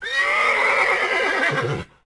Index of /HCU_SURVIVAL/Launcher/resourcepacks/HunterZ_G4/assets/minecraft/sounds/mob/horse
death.ogg